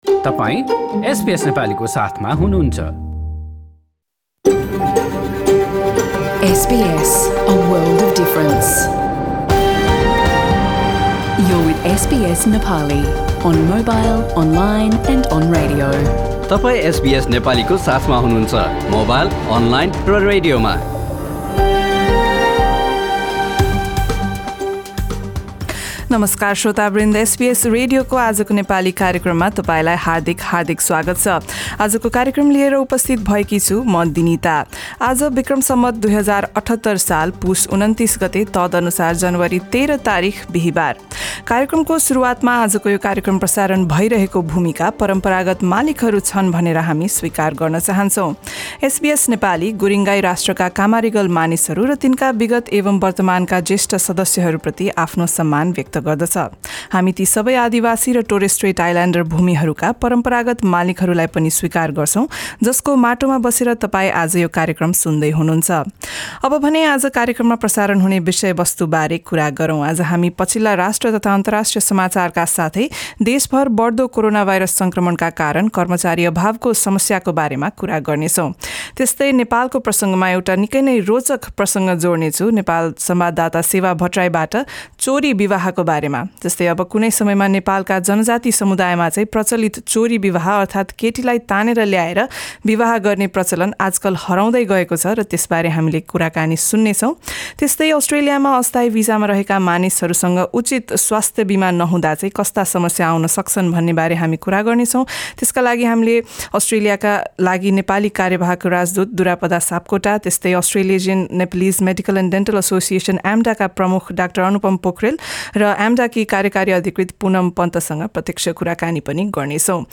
१३ ज्यानुअरी २०२२, बिहीवारको हाम्रो रेडियो कार्यक्रममा अस्ट्रेलिया आउने अस्थायी भिसावाहकलाई किन स्वास्थ्य बिमा आवश्यक छ भन्ने बारे छलफल, अस्ट्रेलियामा ओमिक्रनको प्रभावले व्यापार-व्यवसायमा पारेको असर र नेपालमा 'चोरी विवाह' किन लोप हुँदैछ त भन्ने बारेमा एक रोचक कुराकानी।